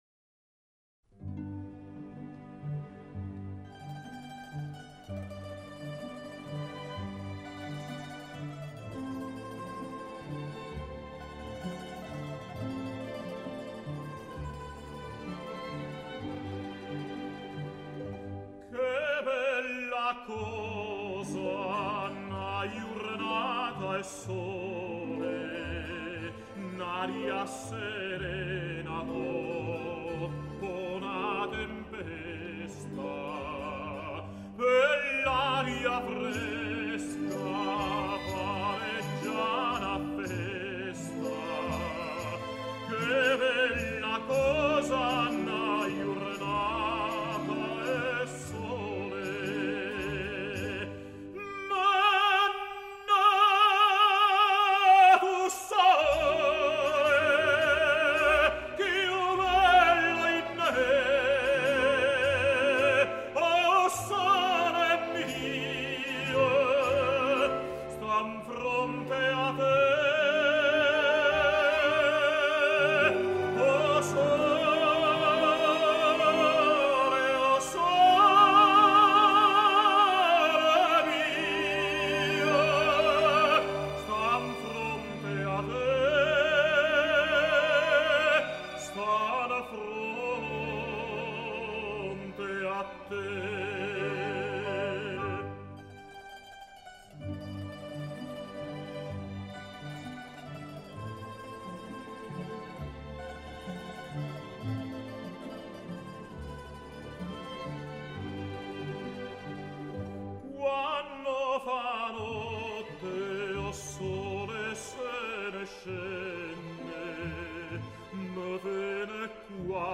男高音
音樂類型：古典音樂
倫敦錄音